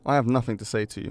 Voice Lines / Dismissive
Update Voice Overs for Amplification & Normalisation